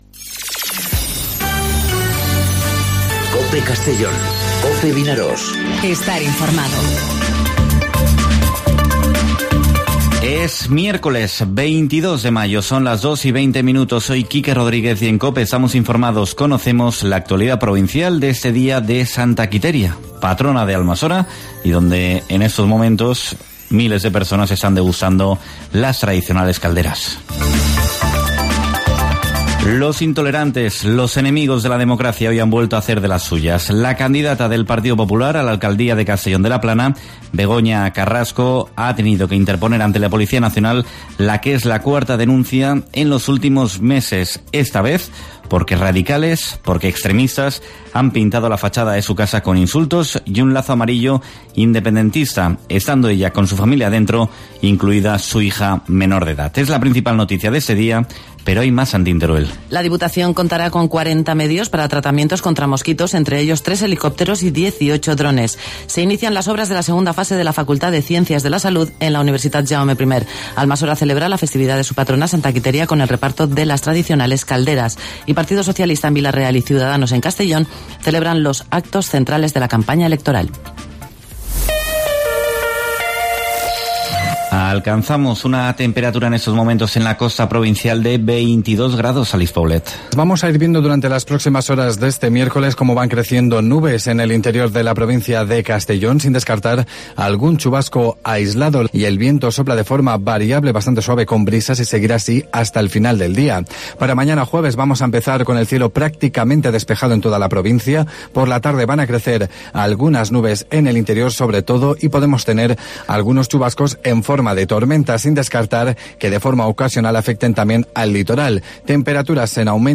Informativo 'Mediodía COPE' en Castellón (22/05/2019)